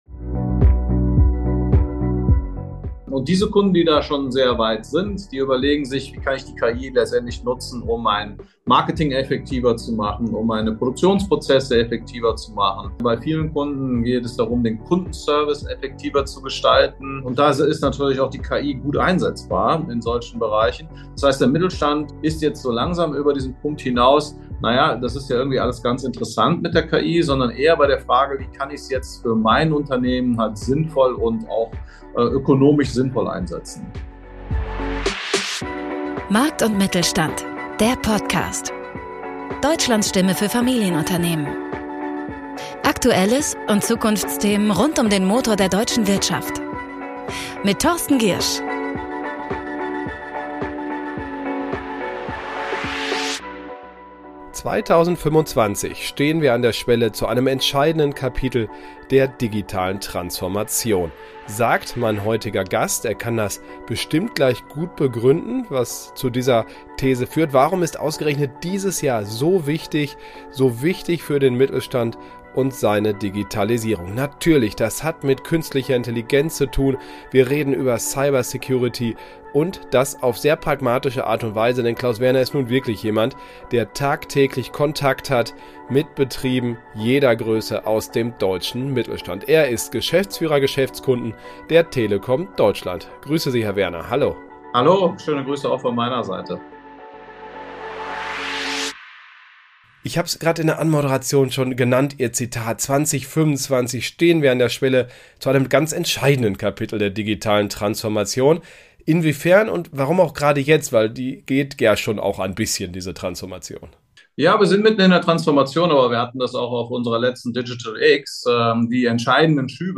Jeden Freitag bieten wir effektiv in rund 15 Minuten zweierlei: Erstens stellen wir kurz und smart zusammen, was in dieser Woche Relevantes aus Sicht eines Mittelständlers passiert ist. Und zweitens sprechen wir mit einem Gast zu einem für den Mittelstand wichtigen Thema – hoher Nutzwert garantiert!